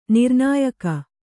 ♪ nirnāyaka